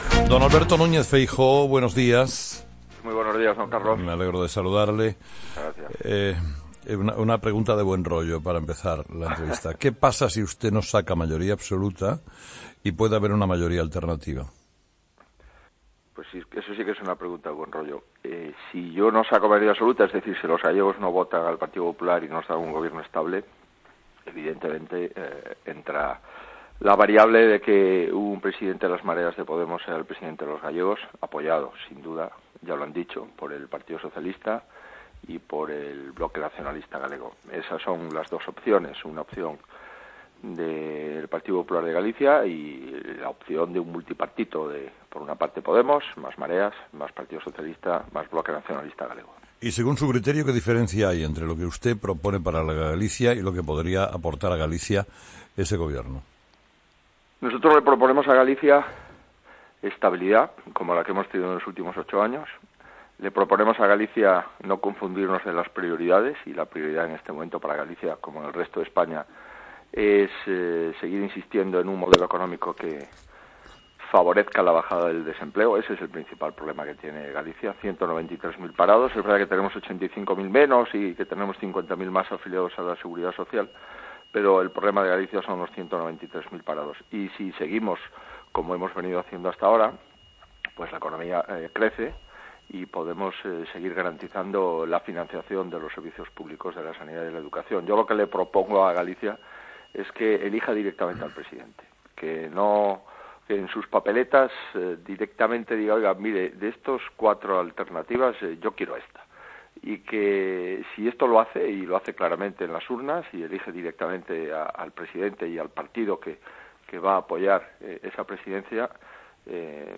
Entrevista a A.Núñez Feijóo COPE
Entrevistado: "Alberto Núñez Feijóo"